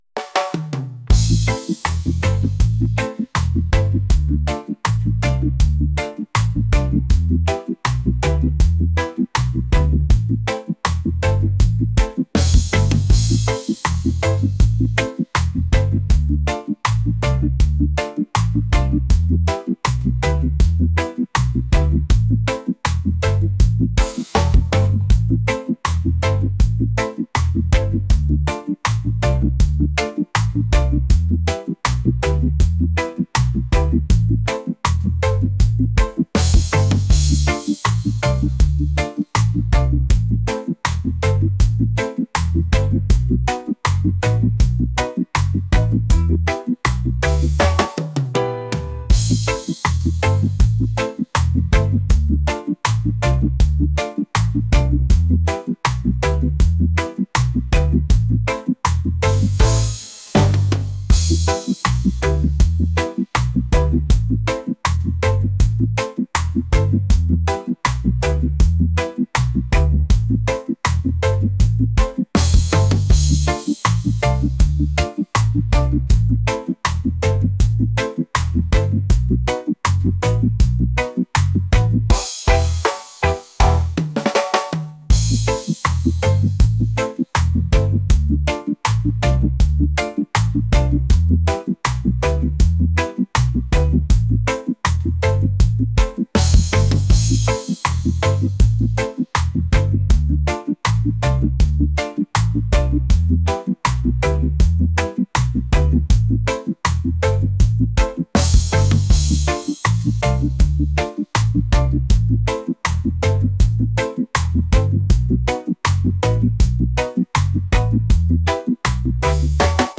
reggae | lounge | jazz